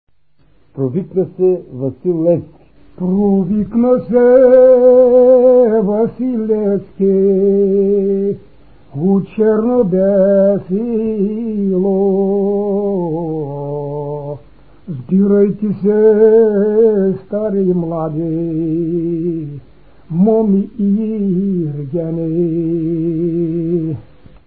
музикална класификация Песен
размер Безмензурна
фактура Едногласна
начин на изпълнение Солово изпълнение на песен
фолклорна област Югоизточна България (Източна Тракия с Подбалкана и Средна гора)
място на записа Горица
начин на записване Магнетофонна лента